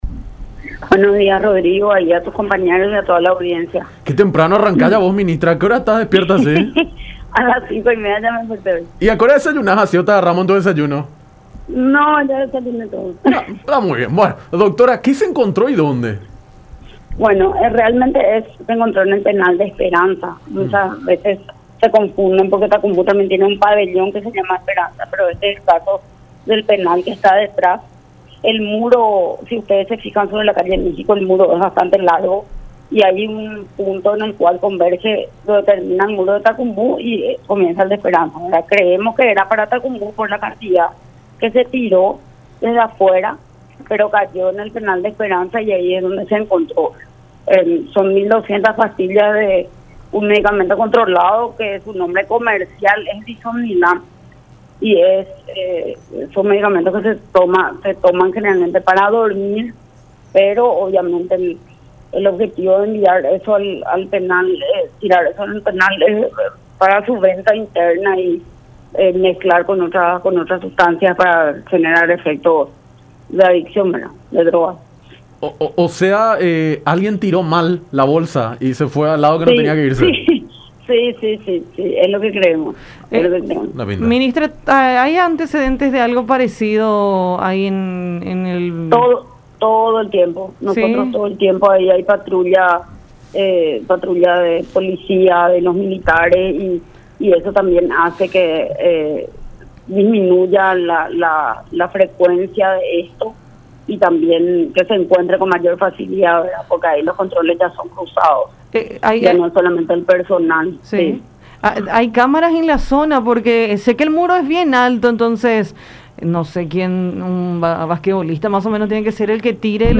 “Se encontró en el Penal Esperanza 1.200 pastillas de Disomnilán, que generalmente se toman para dormir y de uso controlado.  Estimamos que se arrojó con fuerza, pero sin que sea necesaria como para que llegue al penal de Tacumbú, que es adonde creemos que querían enviar”, dijo Cecilia Pérez, ministra de Justicia, en diálogo con Enfoque 800 por La Unión.